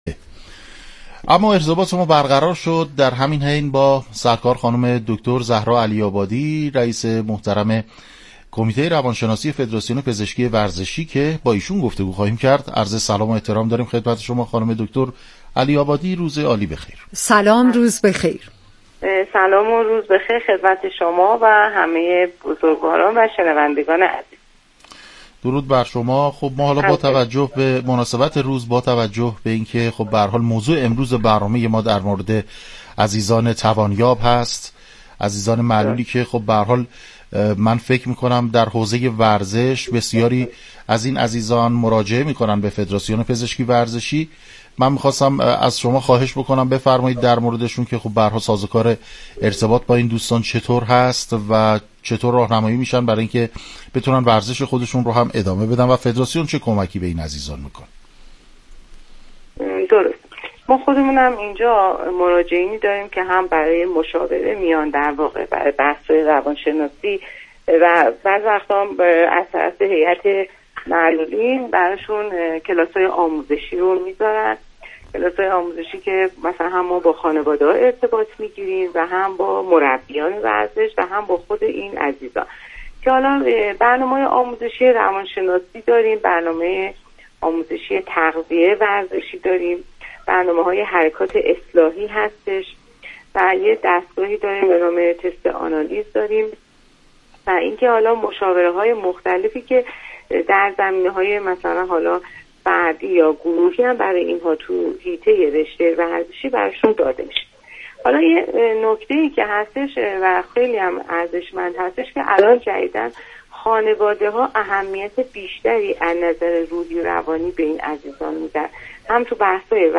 گفتگوی رادیویی